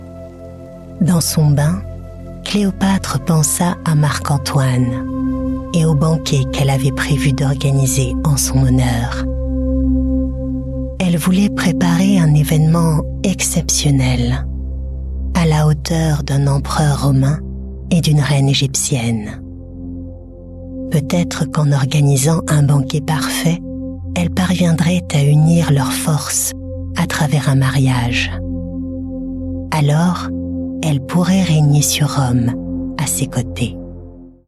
Audiobooks
French voice over actress native from France, neutral accent.
My voice can be natural, sensual, dramatic, playful, friendly, institutional, warm and much more …
Mezzo-Soprano